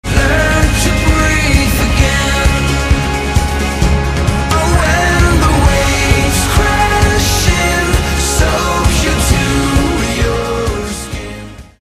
Keyboards
Gitarre
Schlagzeug